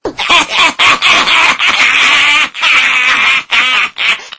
animalhaha
haha_1.mp3